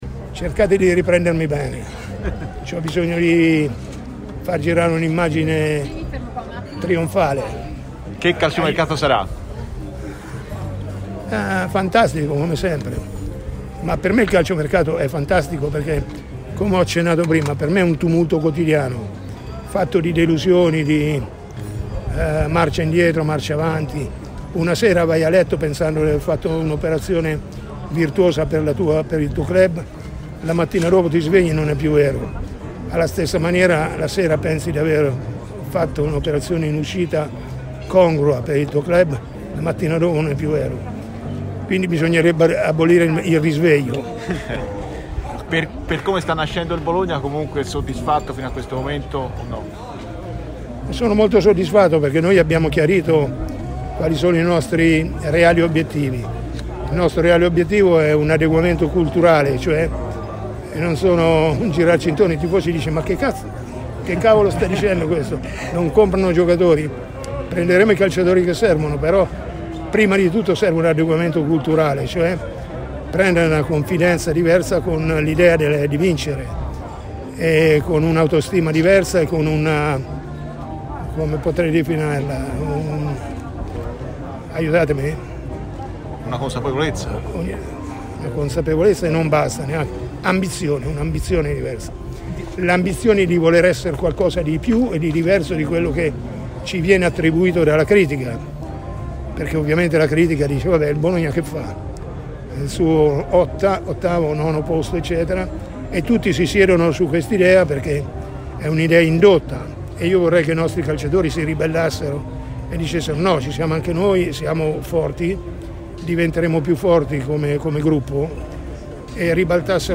è intervenuto in diretta ai microfoni di Stadio Aperto, trasmissione di TMW Radio